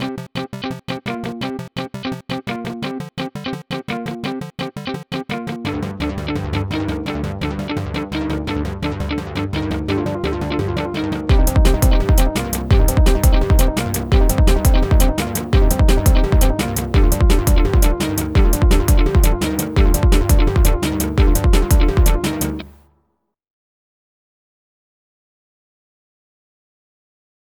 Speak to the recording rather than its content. I created this in KORG Gadget the day I got My New iPad, last year, but never finished it off, or did anything with it.